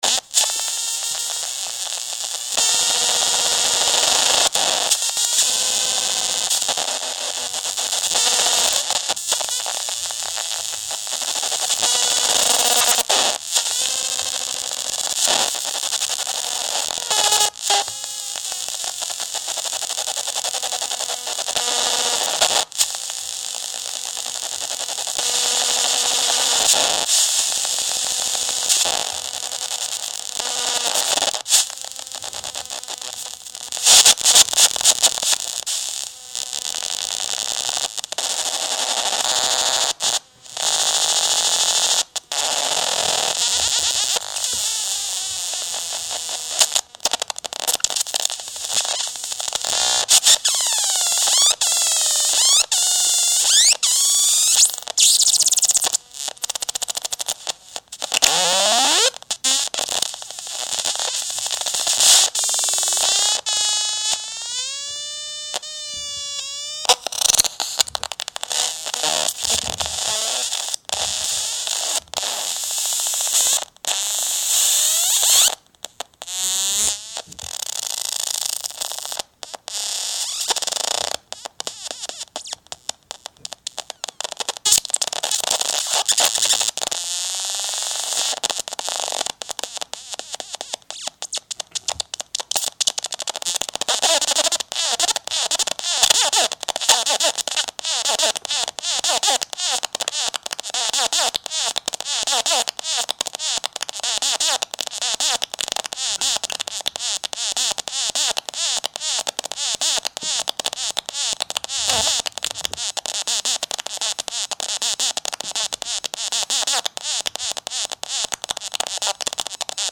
this sounds like giant bugs fighting in a sewer, so dank